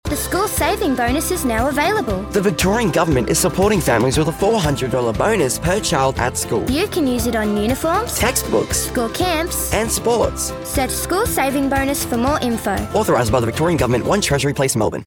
Male Voice Over Talent, Artists & Actors
Teenager (13-17)